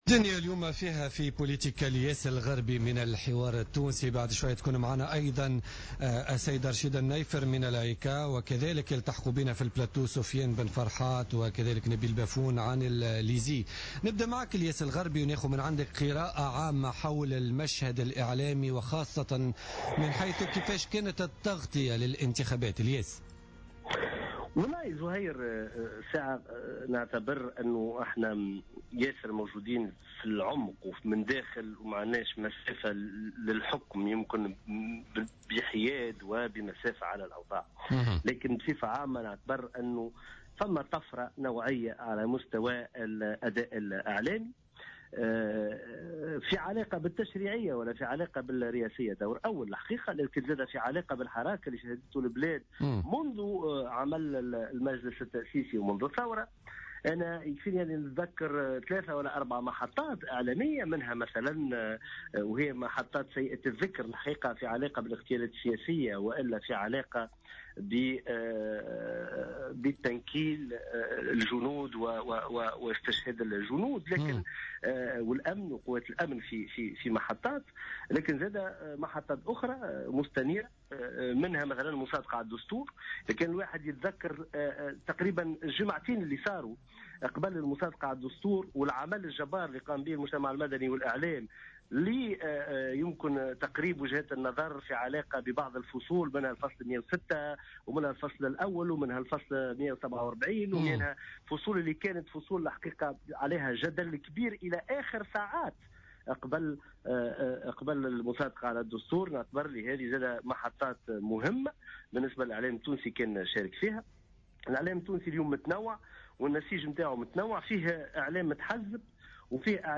أكد الاعلامي بقناة الحوار التونسي الياس الغربي في مداخلة له في برنامج بوليتيكا اليوم الجمعة 28 نوفمبر 2014 أن التغطية الإعلامية للانتخابات تبدو مقبولة في انتظار سن تشريعات جديدة تنظم القطاع وإعادة تأهيل وهيكلة تامة للقطاع برمته وفي انتظار ان يكون لدينا المعدات التقنية التي تمكن وسائل الإعلام من أن تكون موجودة حينيا في مواقع القرار ومواقع التصويت ومع المواطن أينما كان على حد قوله.